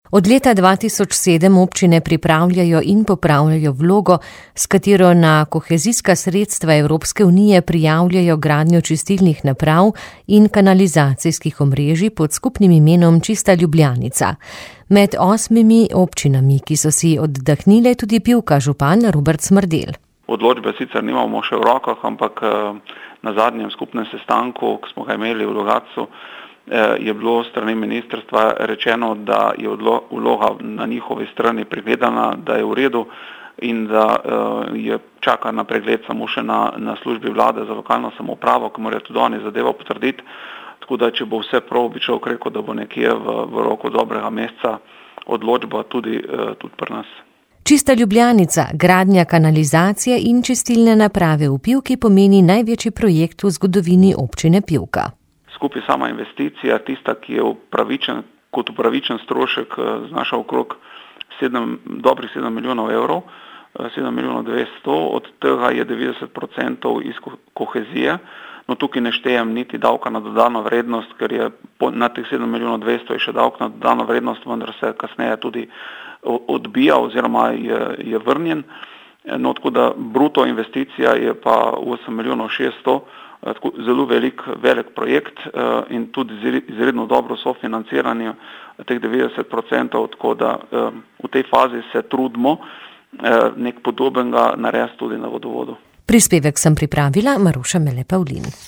• novice radio94 r94
Projekt Čista Ljubljanica, s katerim bodo v Pivki uredili kanalizacijo in čistilno napravo, je največji projekt v zgodovini pivške občine, nam je povedal župan Robert Smrdelj.